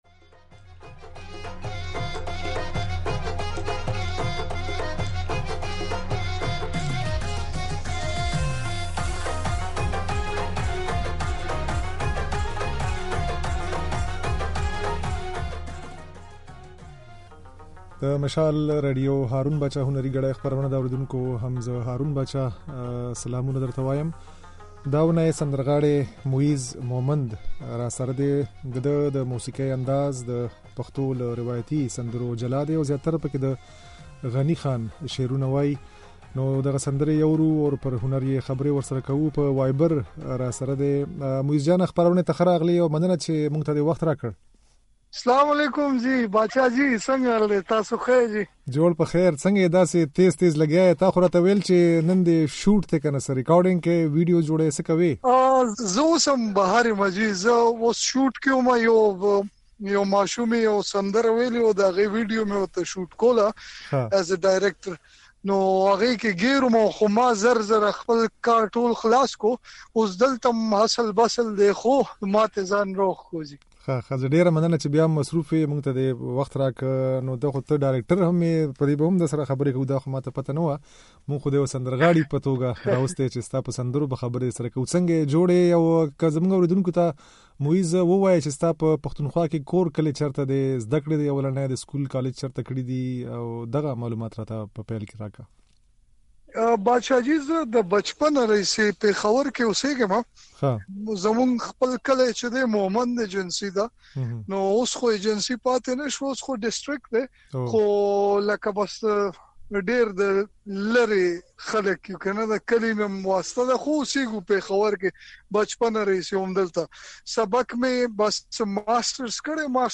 ده شاوخوا شپږ کاله مخکې سندرې پيل کړي او تر دې دمه يې يوازې د غني خان شعرونه ويلي دي. دی وايي، اکثره وخت خپلو کمپوزونو ته احساساتي او په ژړا شي. د نوموړي دا خبرې او ځينې سندرې يې د غږ په ځای کې اورېدای شئ.